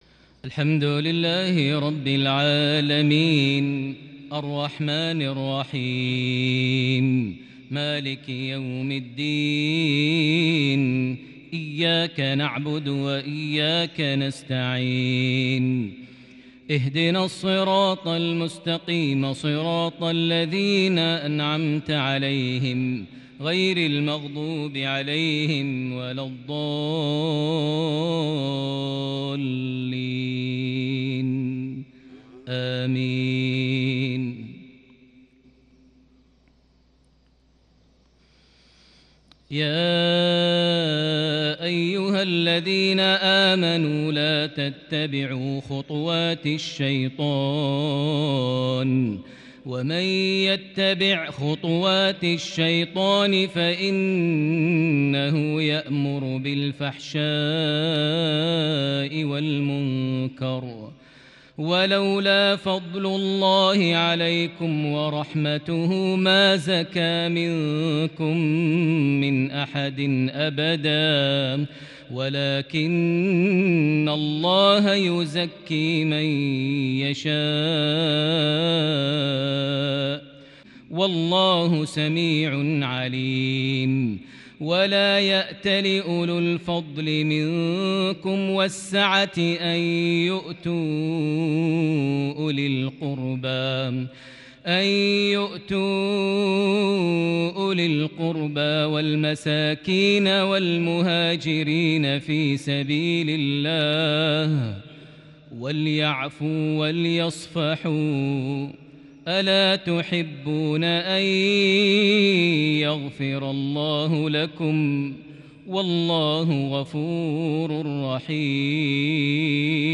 تلاوة بلمحات الكرد الفذ من سورة النور (21-26) | مغرب 24 ربيع الأول 1442هـ > 1442 هـ > الفروض - تلاوات ماهر المعيقلي